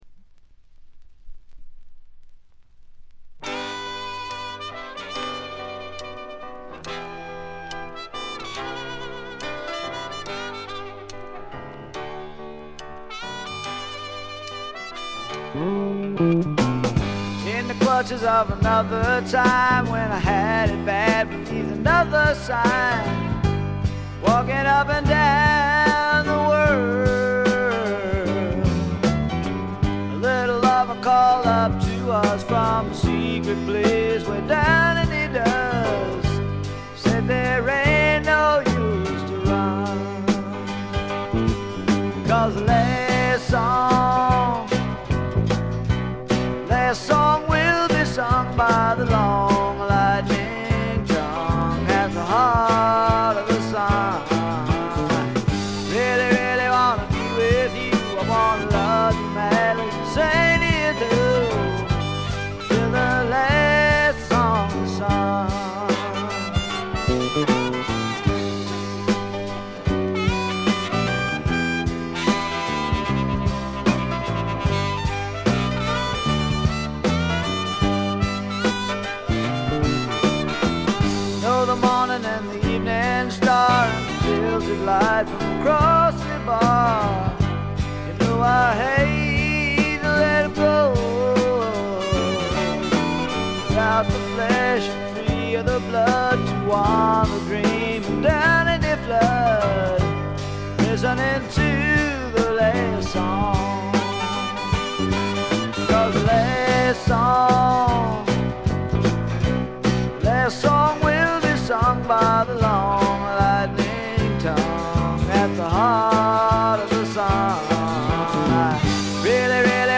ごくわずかなノイズ感のみ。
聴くものの心をわしづかみにするような渋みのある深いヴォーカルは一度聴いたら忘れられません。
試聴曲は現品からの取り込み音源です。